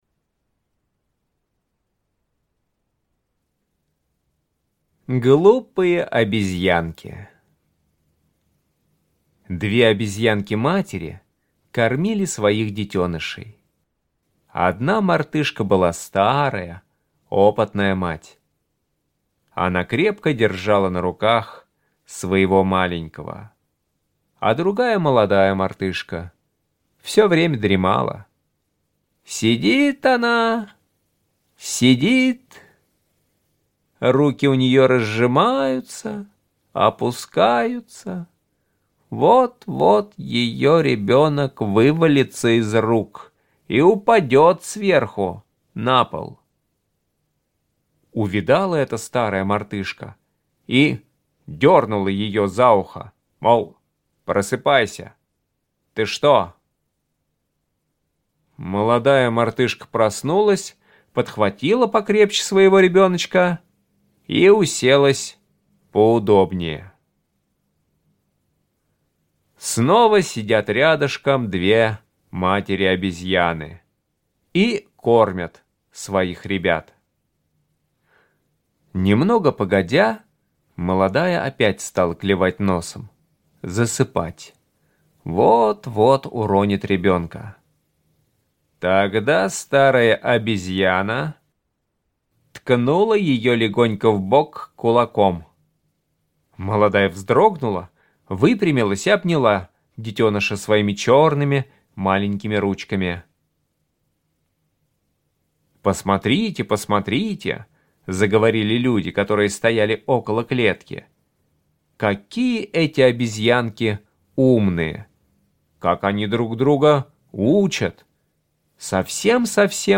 Аудиорассказ «Глупые обезьянки»